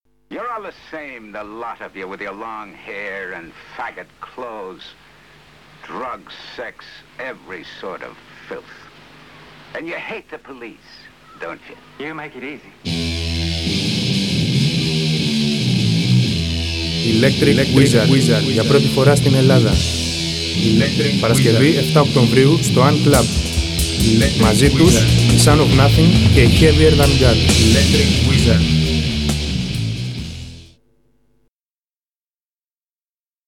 Radio Spot // Press Release